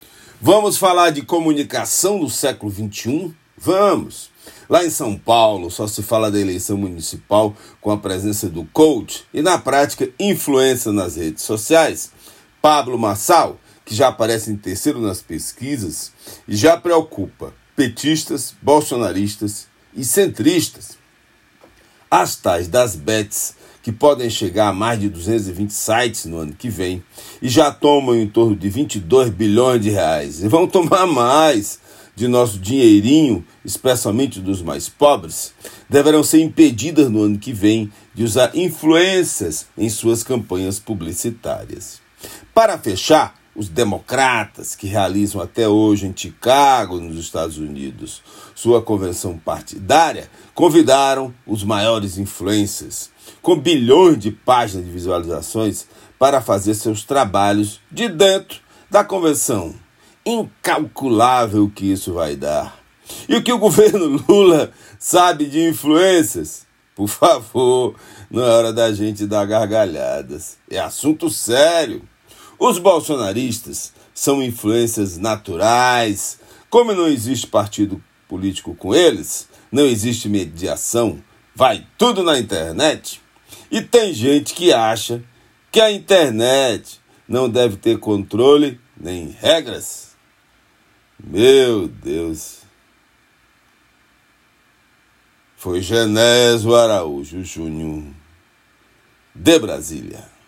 direto de Brasília.